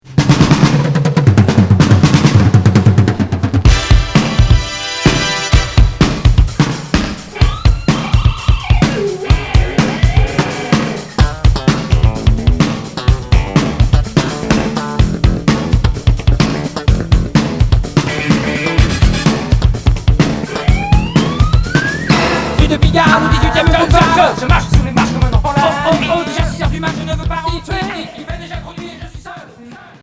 Hard FM Unique 45t retour à l'accueil